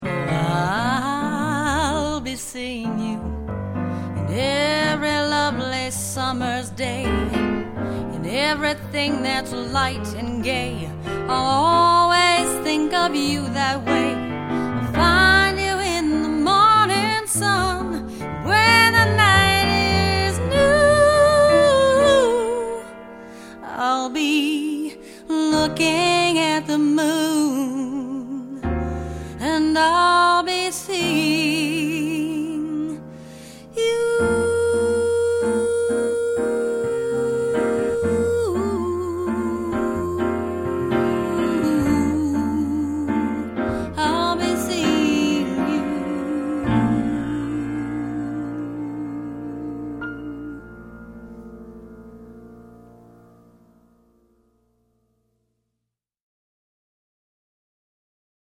A night of jazz to remember.